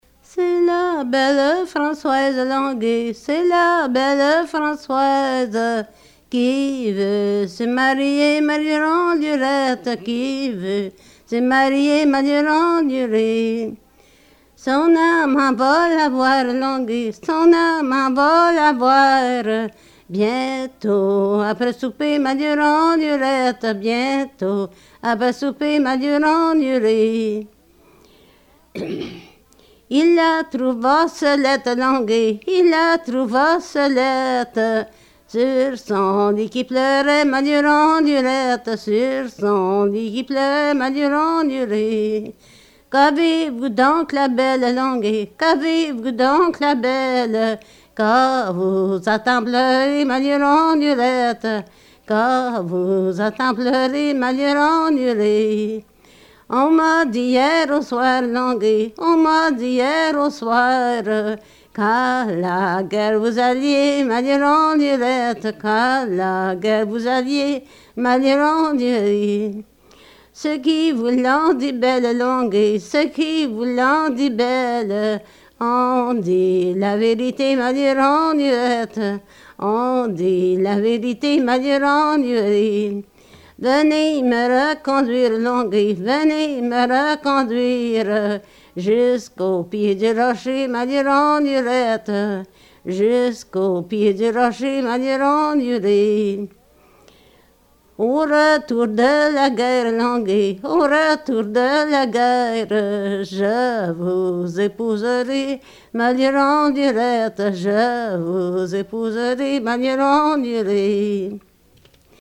Folk Songs, French--New England
sound cassette (analog)